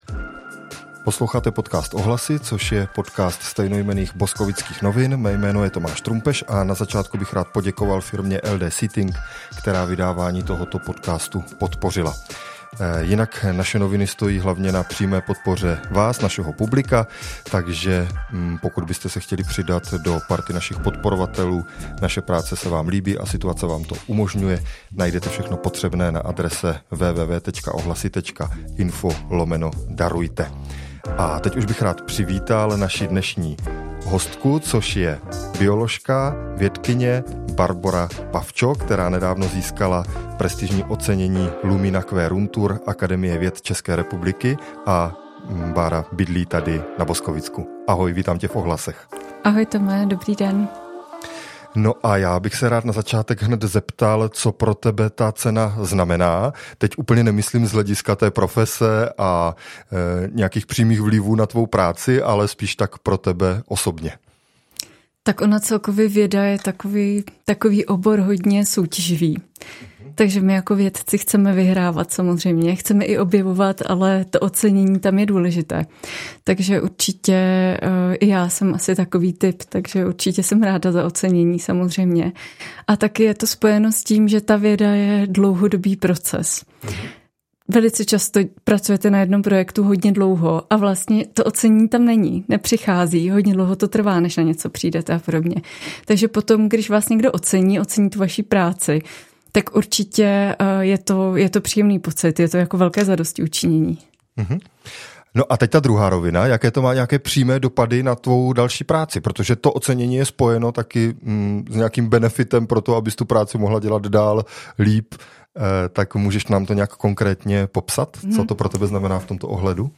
Rozhovor s vědkyní